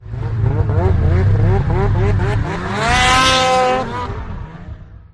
Descarga de Sonidos mp3 Gratis: moto de nieve.
snow-mobile.mp3